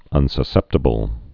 (ŭnsə-sĕptə-bəl)